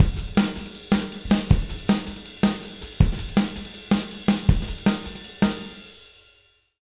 160JUNGLE2-R.wav